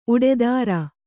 ♪ uḍedāra